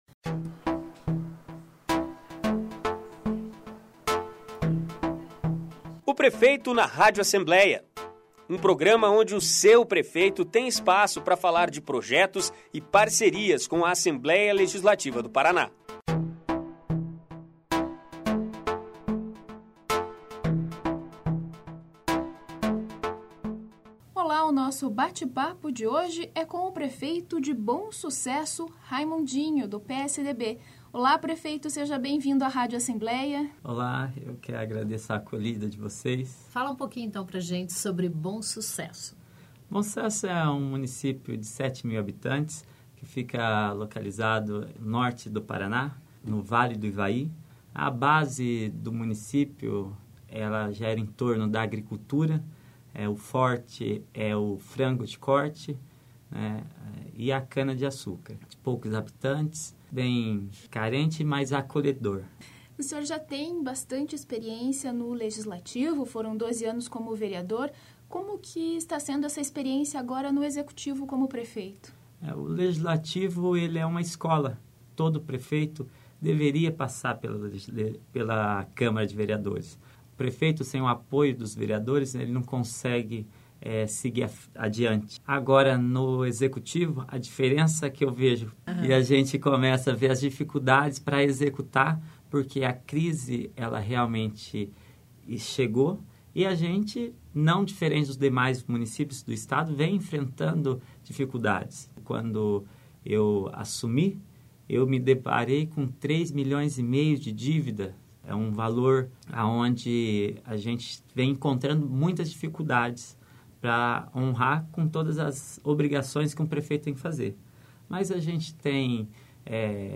Ouça a entrevista do prefeito Raimundinho (PSDB), de Bom Sucesso,à  Rádio Alep esta semana no programa "O Prefeito na Rádio Alep".